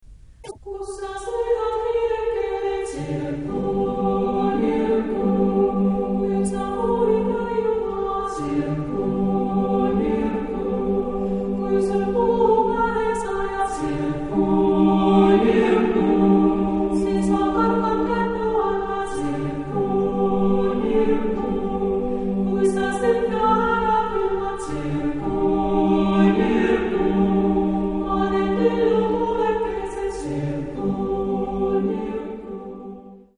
Genre-Style-Form: Popular ; Children ; Partsong
Mood of the piece: andantino ; lively
Type of Choir: SATB  (4 children voices )
Tonality: G minor